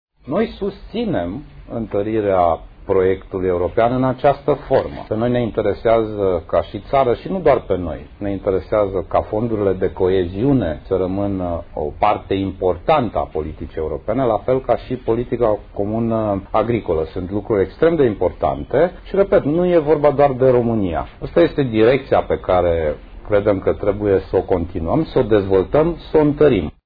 Prim-ministrul Sorin Grindeanu a declarat la Bruxelles, după reuniunea socialiștilor europeni, că în dezbaterea privind viitorul Europei cu mai multe viteze sau nu, socialiștii nu au o opinie comună.
La o întrebare Radio România, primul ministru a explicat că nu se pune problema vreunei schimbari la nivel european în ce privește fondurile structurale și de coeziune.